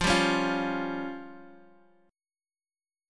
Fsus2#5 chord